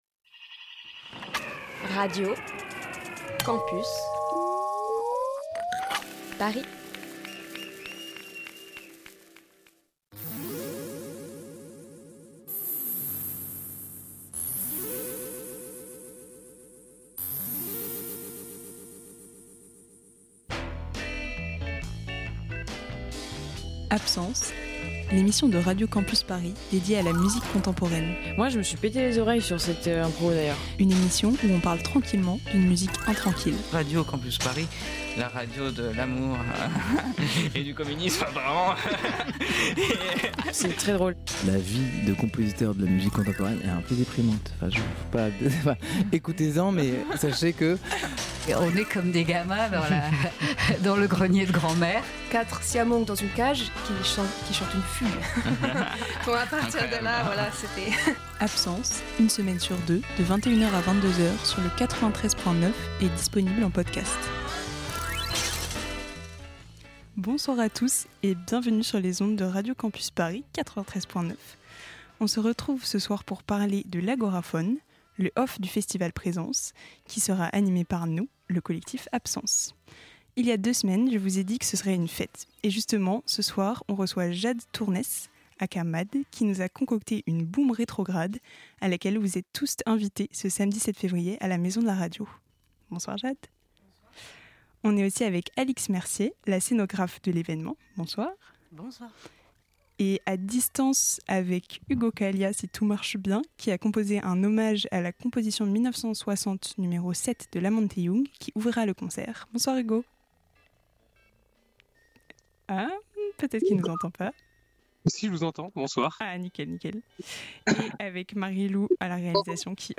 Type Musicale Courants Alternatifs Classique & jazz